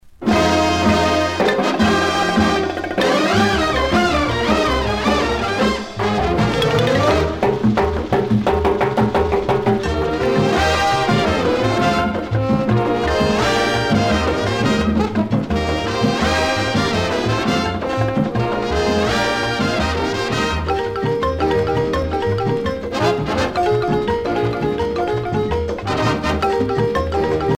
danse : mambo